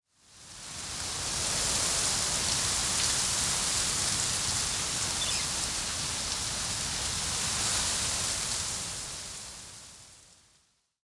windtree_7.ogg